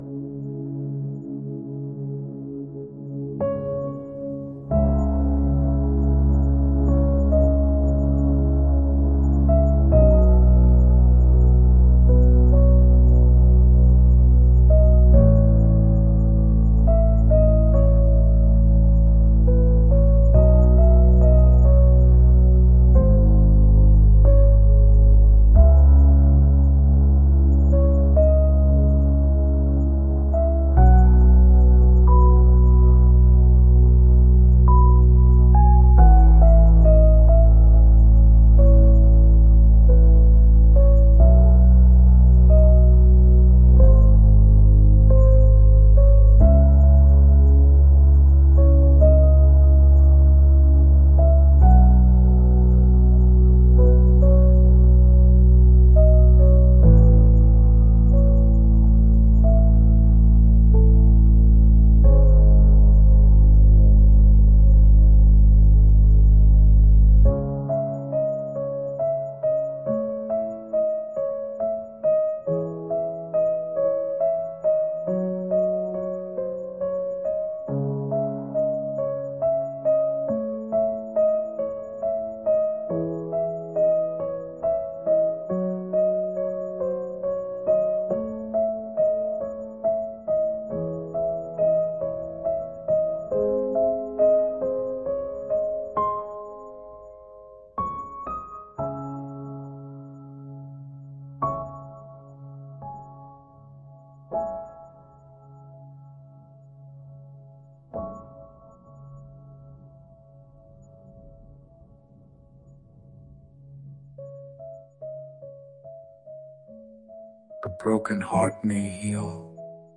THEFTS FROM YOUR HEART - (Long Musical intro)